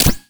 pgs/Assets/Audio/User_Interface_Menu/ui_stamp_01.wav at master
ui_stamp_01.wav